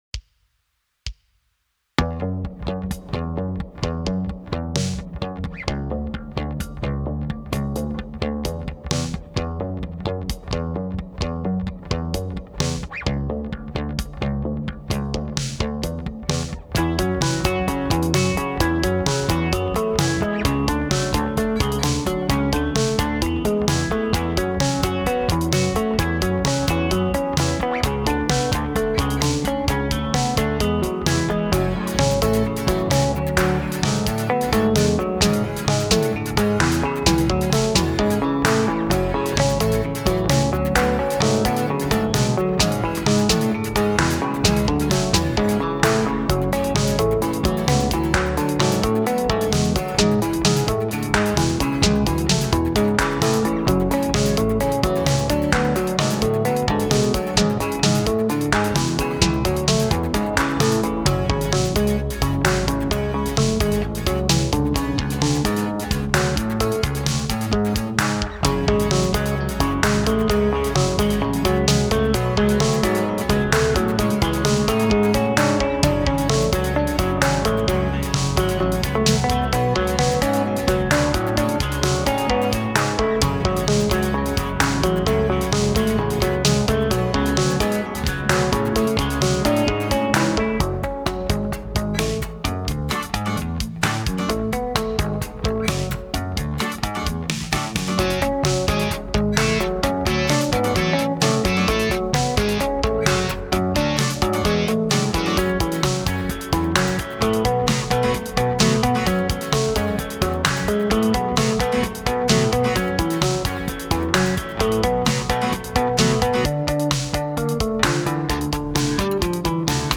В нем я использовал живой бас и разные семплированные инструменты.